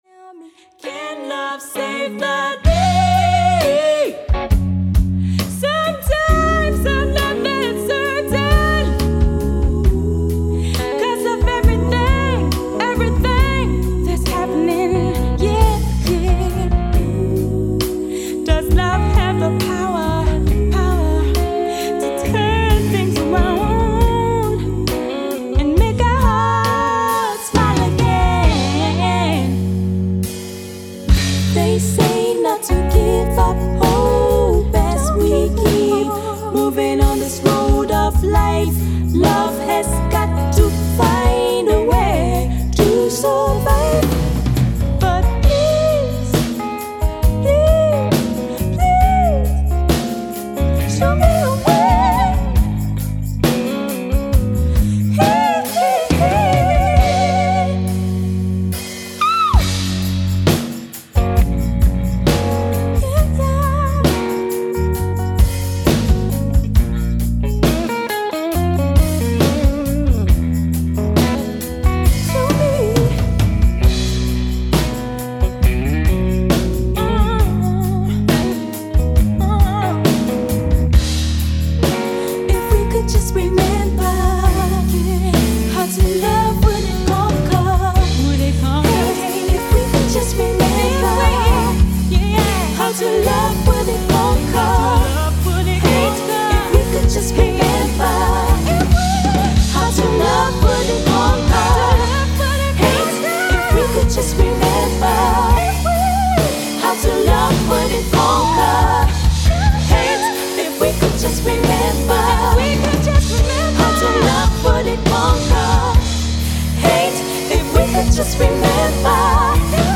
Rock&Blues